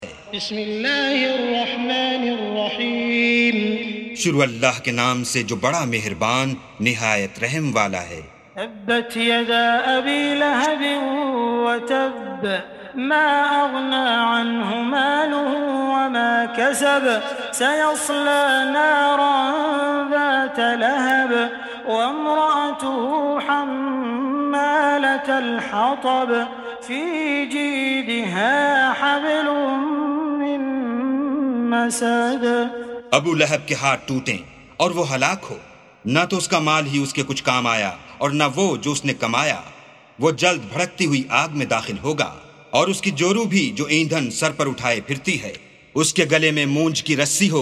سُورَةُ المَسَدِ بصوت الشيخ السديس والشريم مترجم إلى الاردو